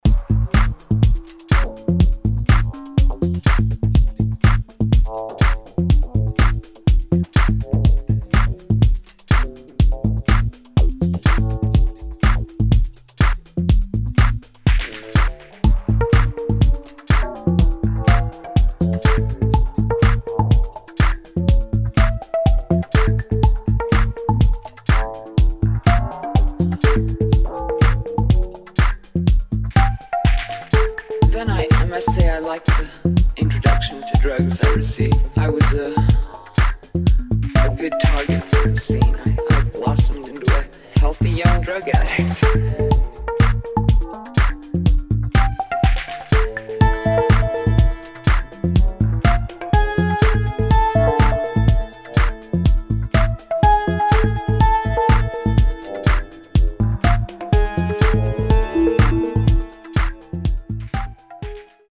[ DEEP HOUSE | TECH HOUSE ]